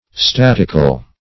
Statical - definition of Statical - synonyms, pronunciation, spelling from Free Dictionary
Static \Stat"ic\ (st[a^]t"[i^]k), Statical \Stat"ic*al\
(-[i^]*kal), a. [Gr. statiko`s causing to stand, skilled in